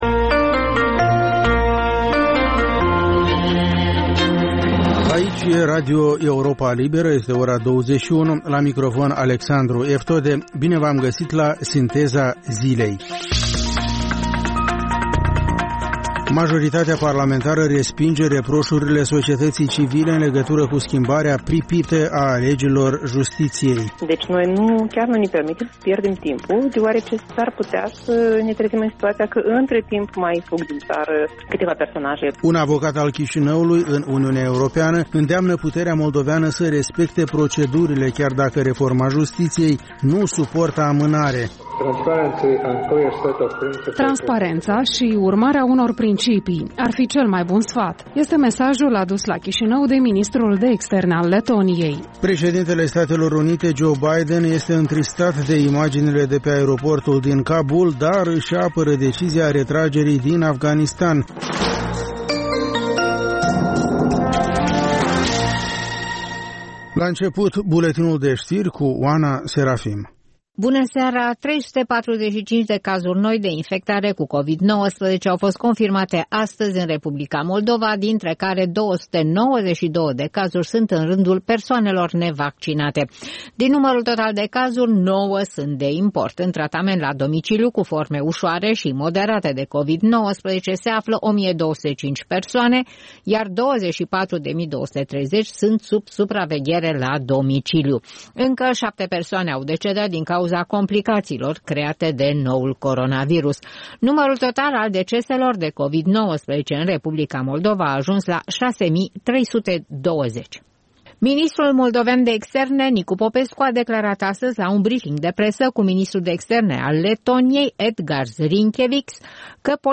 Ştiri, interviuri, analize şi comentarii.